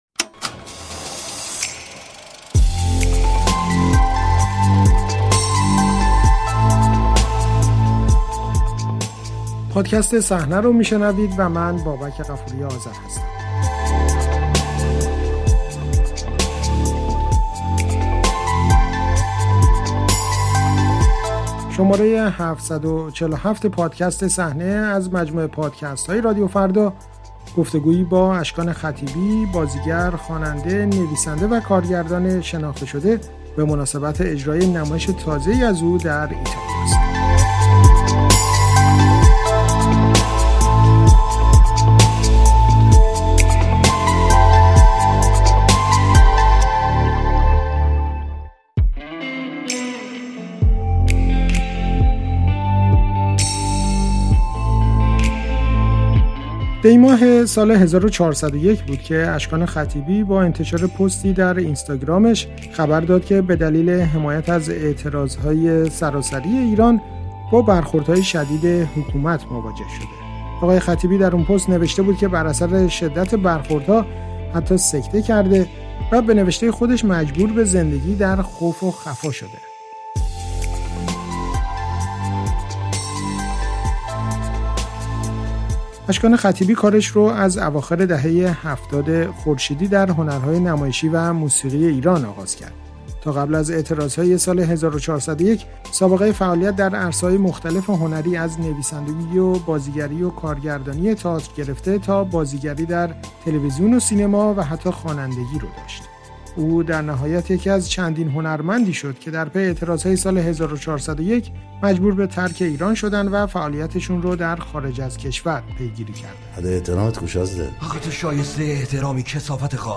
اشکان خطیبی در گفت‌وگو با رادیوفردا که اولین گفت‌وگوی رسانه‌ای او پس از خروج از کشور است، از دلایل خروجش، حمایت از معترضان و کارهای قبلی‌اش در ایران و اکنونش در ایتالیا گفته است.